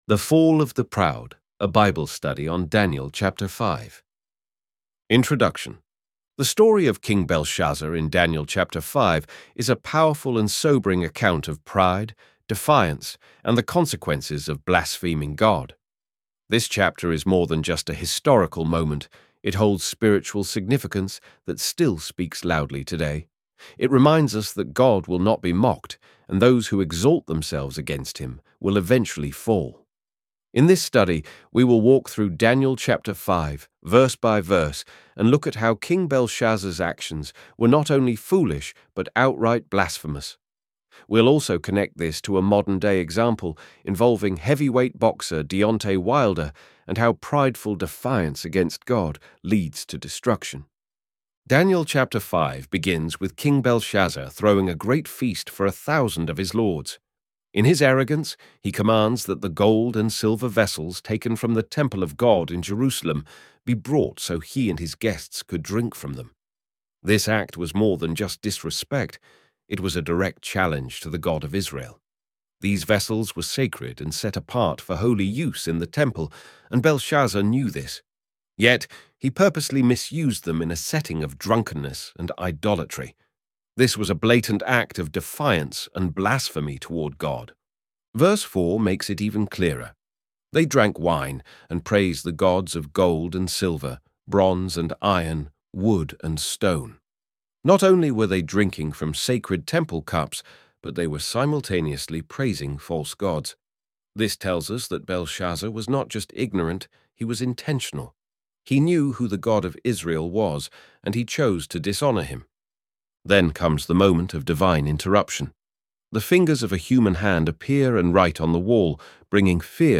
ElevenLabs_daniel_5.mp3